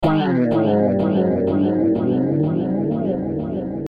标签： 120 bpm Blues Loops Guitar Electric Loops 678.81 KB wav Key : Unknown
声道立体声